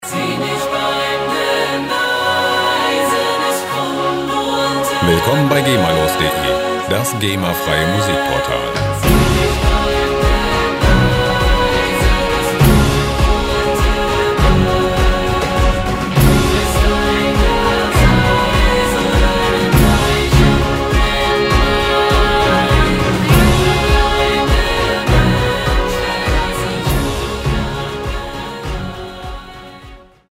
epische Musikloops lizenzfrei
Musikstil: Symphonic Rock
Tempo: 101 bpm